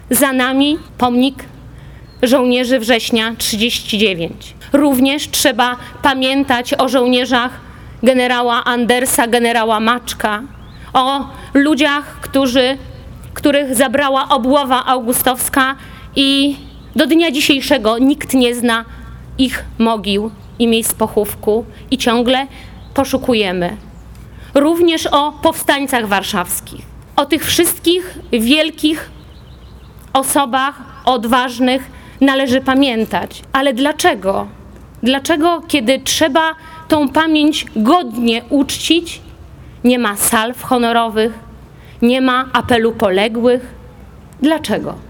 Był hymn, modlitwa, składanie kwiatów i przemówienia, podczas których głos zabrali Bożena Kamińska, parlamentarzystka Platformy Obywatelskiej i Czesław Renkiewicz, prezydent Suwałk.